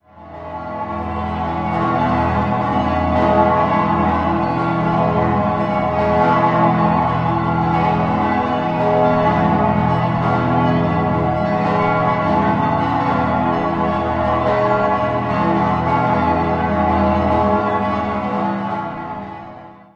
14-stimmiges Geläute: e°-gis°-h°-cis'-fis'-gis'-ais'-h'-cis''-e''-fis''-gis''-a''-h''